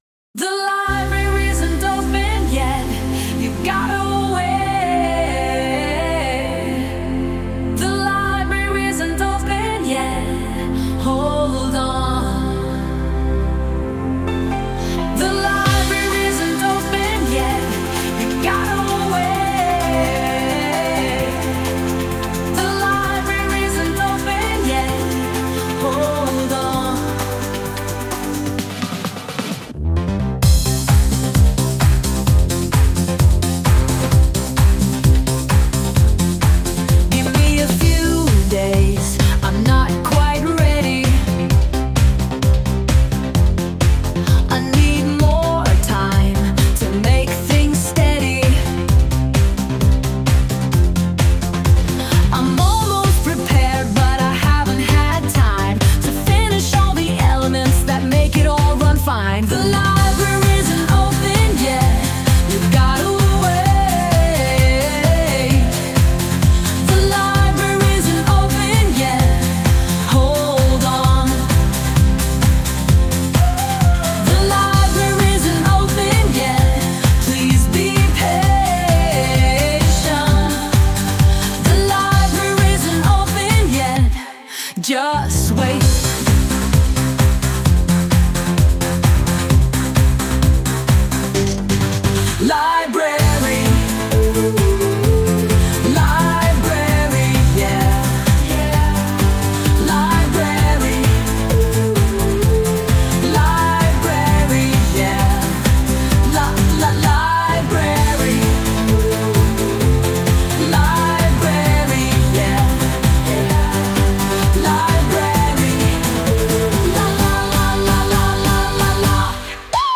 Sound Imported : Transindental Expedition
Sung by Suno